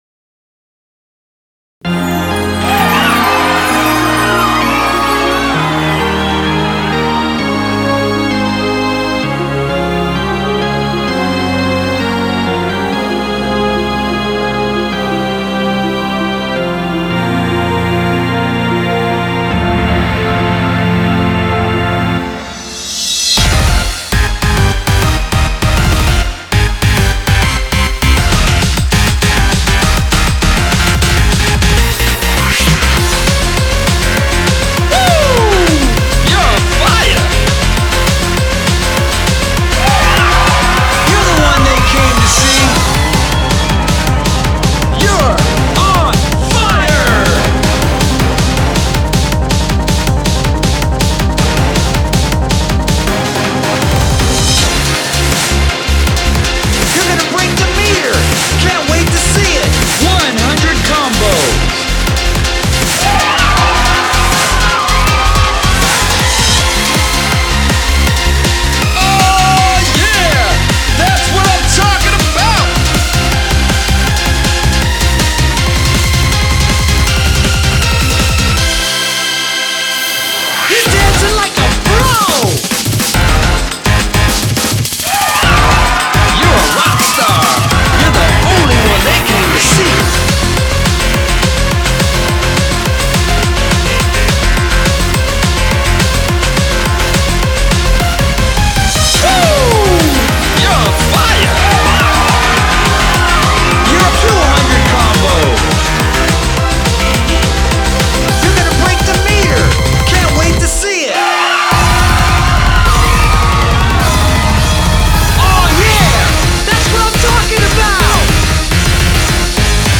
BPM23-840
Audio QualityLine Out